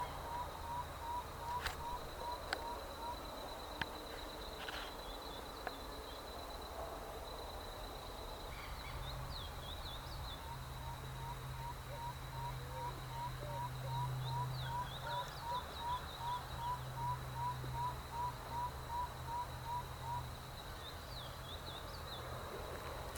Spotted Bamboowren (Psilorhamphus guttatus)
171004_018-Gallito-Overo.mp3
Location or protected area: Bio Reserva Karadya
Condition: Wild
Certainty: Observed, Recorded vocal